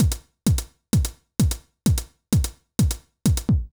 INT Beat - Mix 20.wav